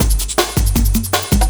06 LOOP11 -L.wav